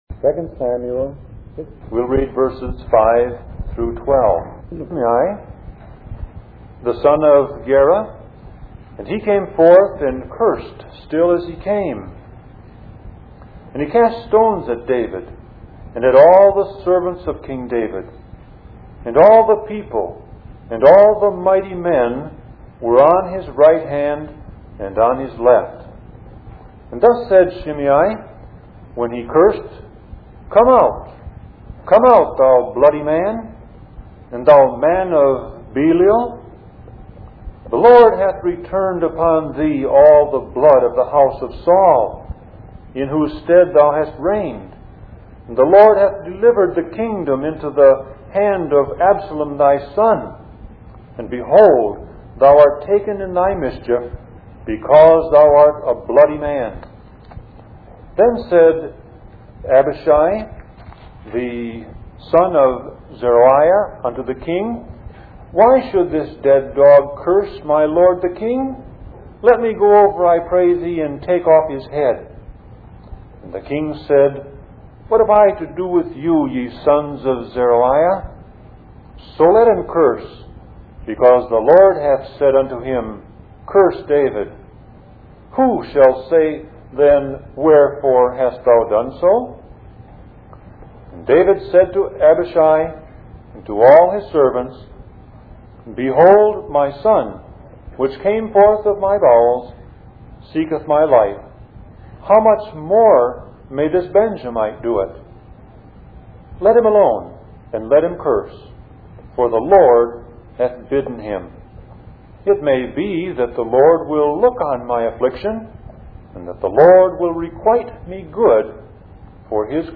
Sermon Audio Passage: 2 Samuel 16:5-12 Service Type